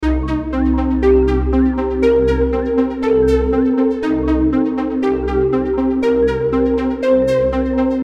Warm Sequential Synth Music Loop 120 BPM
This smooth and uplifting synth loop creates a cozy, melodic atmosphere.
Genres: Synth Loops
Tempo: 120 bpm
Warm-sequential-synth-music-loop-120-bpm.mp3